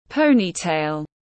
Tóc đuôi ngựa tiếng anh gọi là ponytail, phiên âm tiếng anh đọc là /ˈpəʊ.ni.teɪl/ .
Ponytail /ˈpəʊ.ni.teɪl/